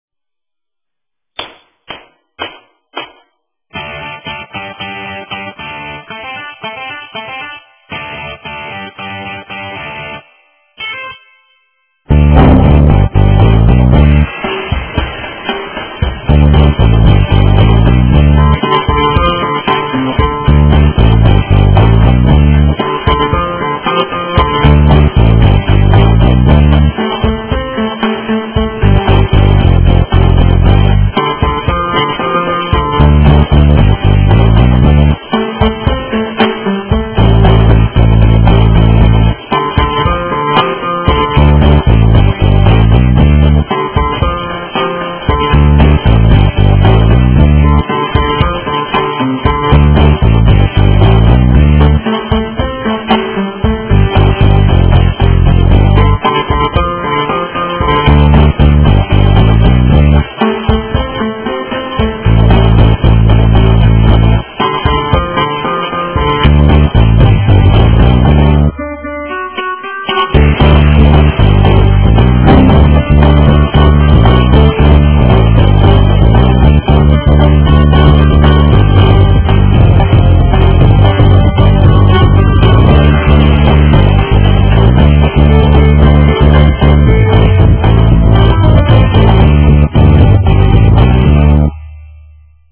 SOLO PART